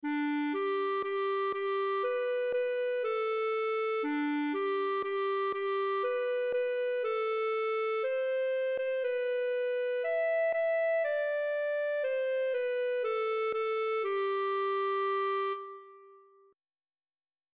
Voci (mp3): sopran, alto, tenor, bas, cor mixt